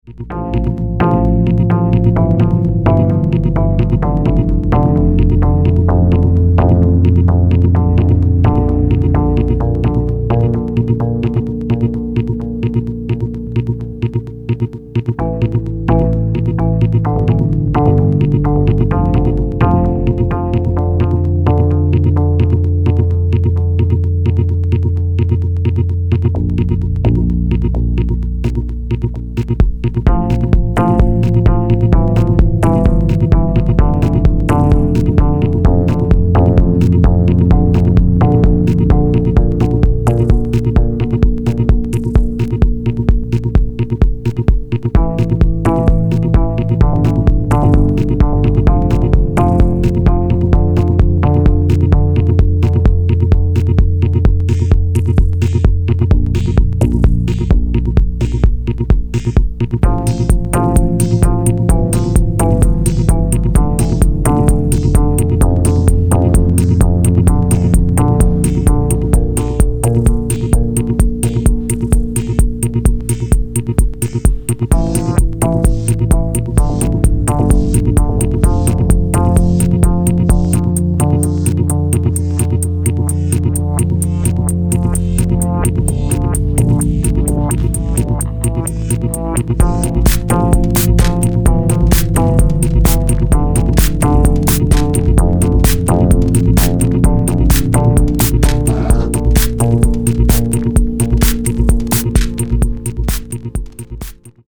妖艶に蠢くアヴァンギャルドな音像、あくまでもファンキーでしなやかなハウスビート。